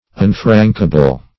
Search Result for " unfrankable" : The Collaborative International Dictionary of English v.0.48: Unfrankable \Un*frank"a*ble\, a. Not frankable; incapable of being sent free by public conveyance.